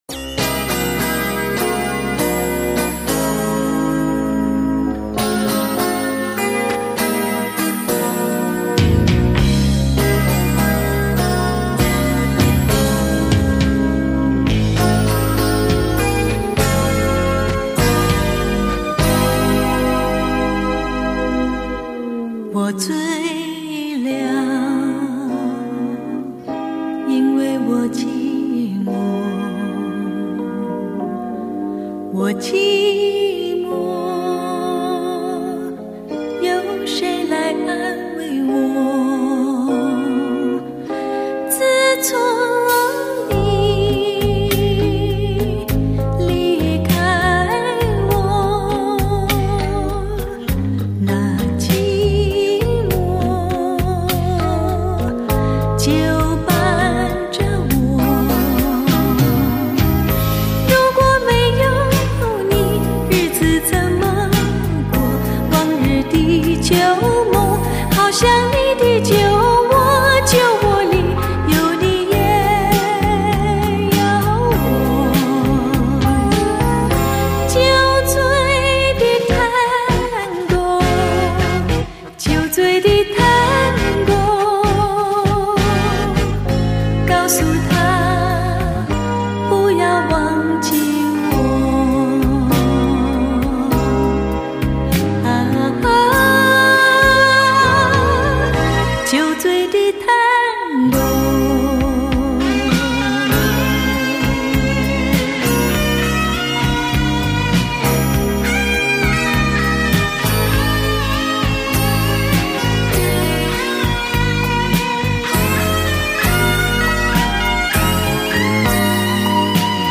由此，可以在普通的CD机上聆听到DSC-CD带来的更清晰、更富原声的天籁之音，令普通的音响系统焕发出前所未有的崭新能量。